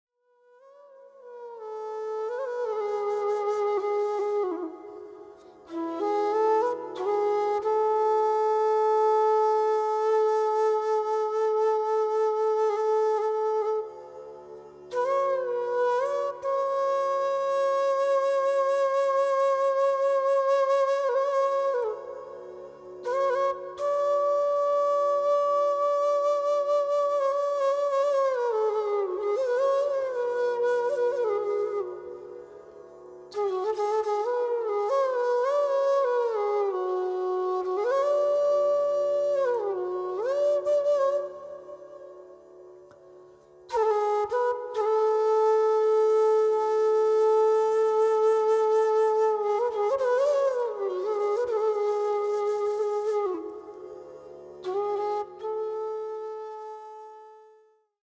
Music type: Meditation Flow
Live recording at: INDeco Swamimalai